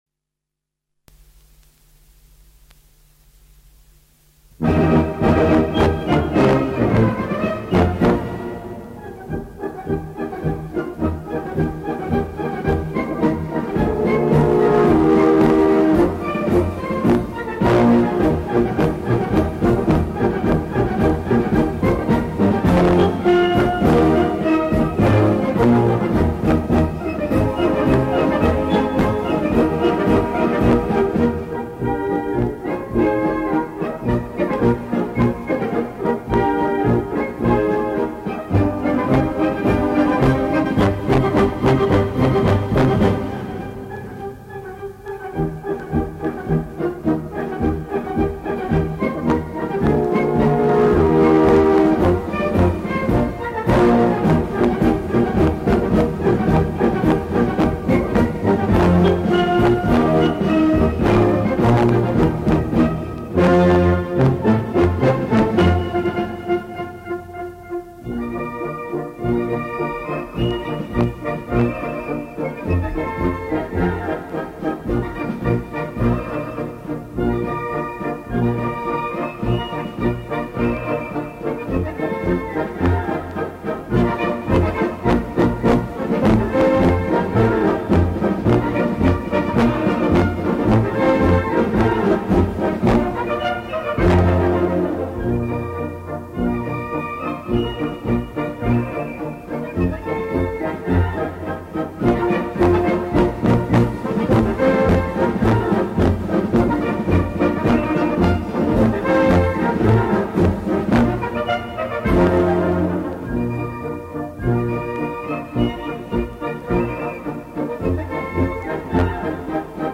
Gattung: Marsch
A4 Besetzung: Blasorchester Zu hören auf